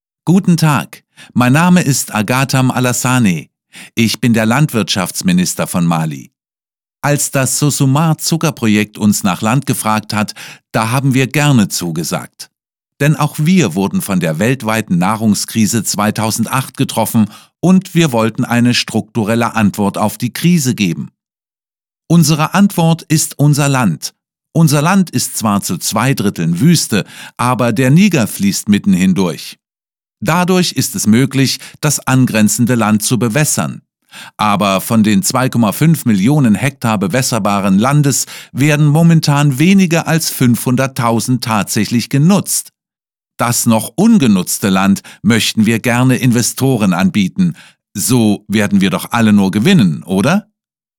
• A national politician says that most of the land being cultivated by the agribusiness is unused and, therefore, it is not harming small-scale farmers.